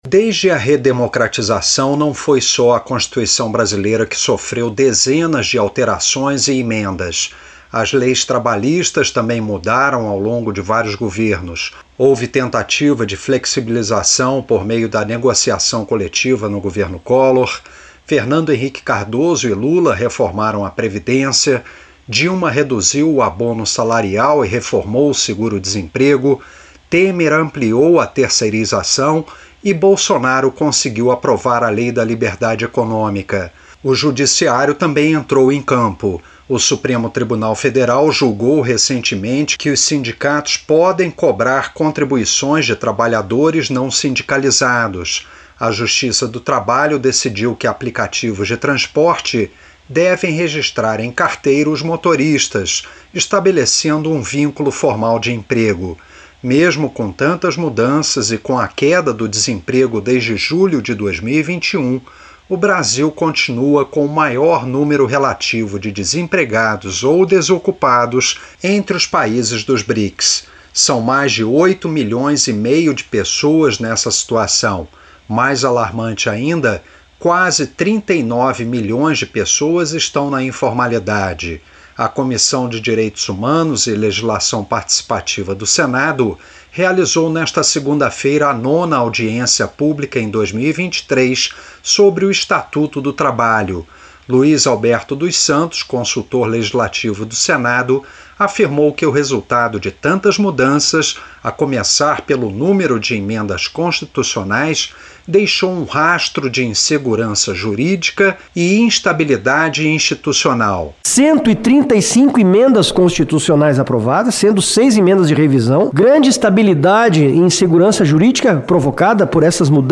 Participantes de audiência pública na Comissão de Direitos Humanos e Legislação Participativa do Senado (CDH) afirmaram, nesta segunda-feira (25), que o mercado de trabalho no Brasil está muito desestruturado. Na opinião dos especialistas, a transformação digital e a inteligência artificial irão criar ainda mais desafios para os trabalhadores.